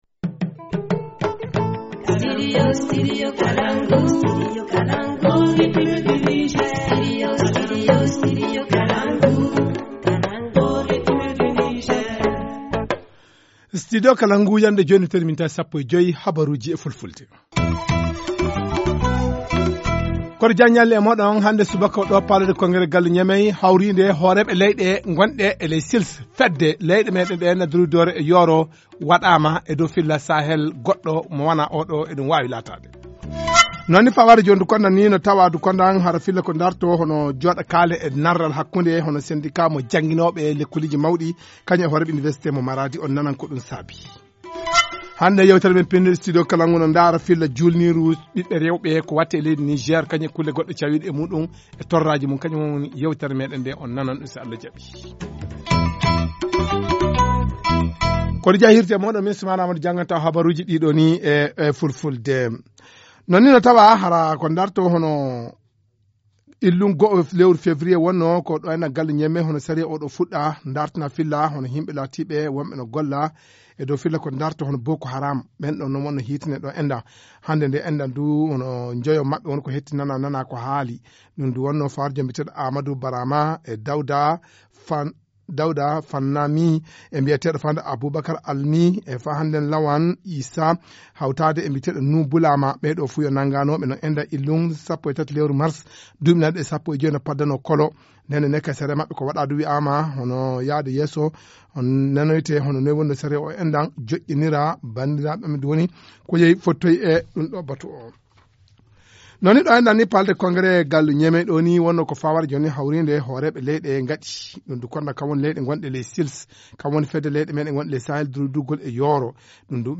Journal du 7 février 2018 - Studio Kalangou - Au rythme du Niger